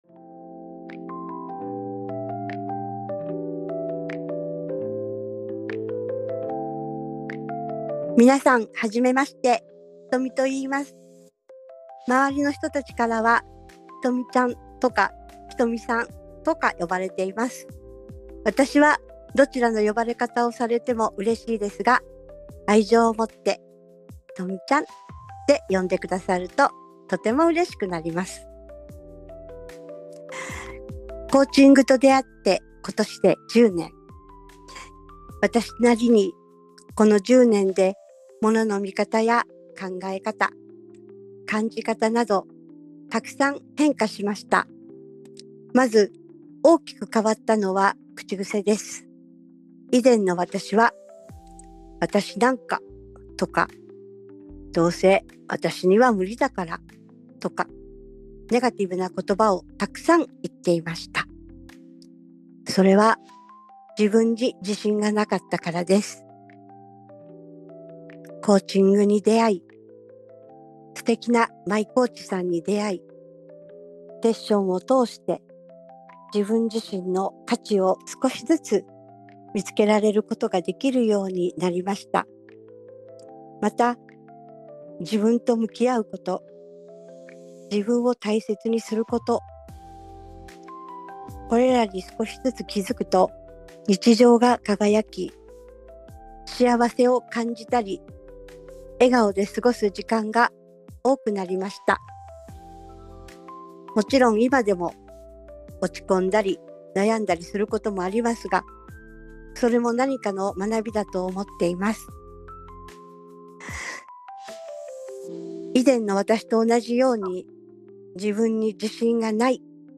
コーチからのメッセージ